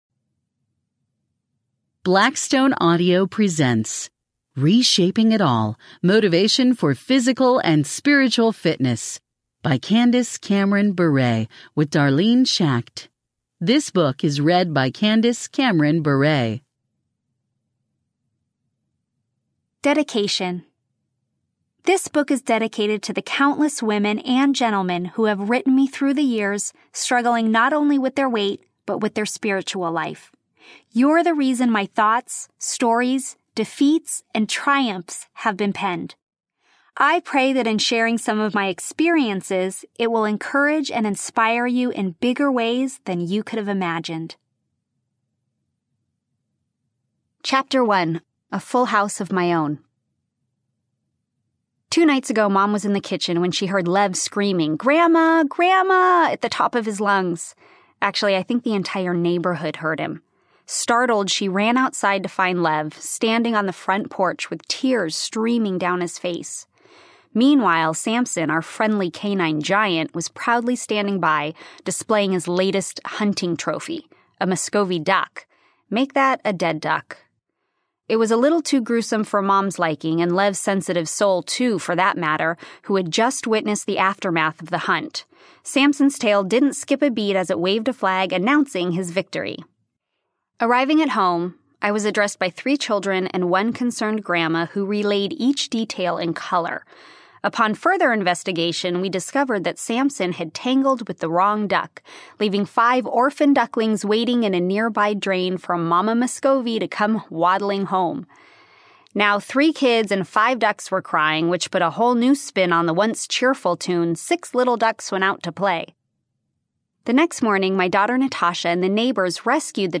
Reshaping It All Audiobook
Narrator